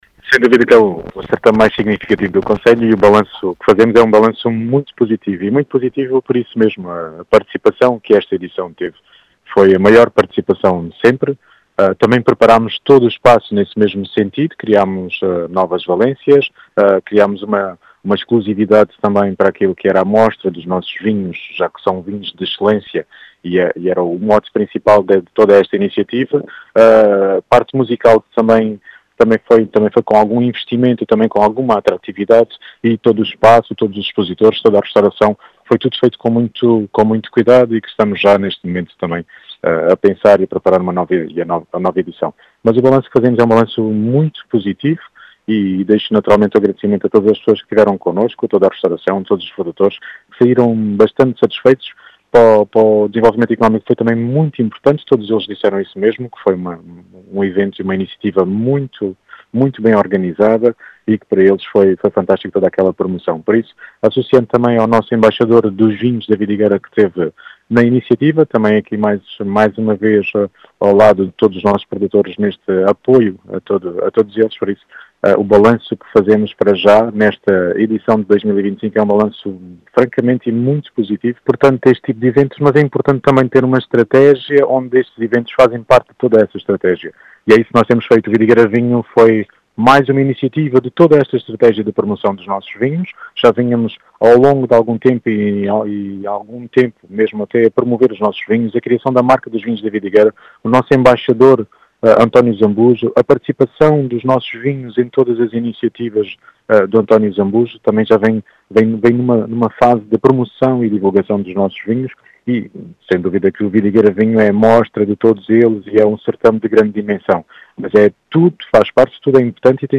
O certame que decorreu ao longo de três dias, terminou ontem, e fechou com um balanço “muito positivo”, segundo explicou à Rádio Vidigueira, o presidente da Câmara Municipal de Vidigueira, Rui Raposo, que salientou a importância do certame para o setor, que atravessa “algumas dificuldades”, bem como a estratégia delineada pela autarquia.